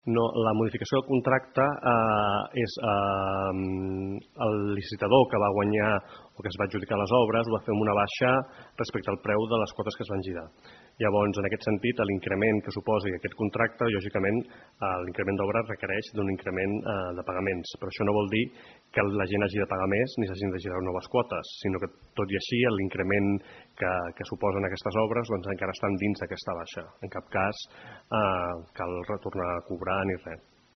Són declaracions de Josep Rueda, regidor d’urbanisme de l’Ajuntament de Palafolls.
Josep Rueda, regidor d’urbanisme